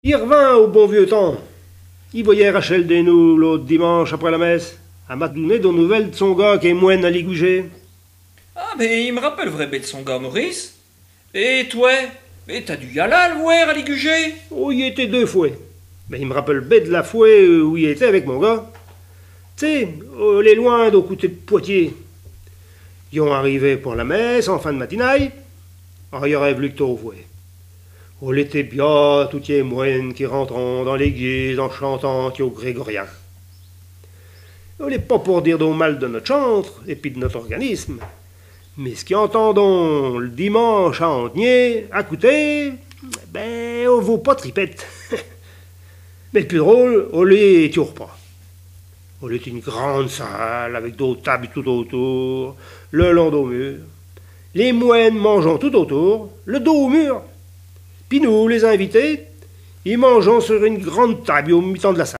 Patois local
sketch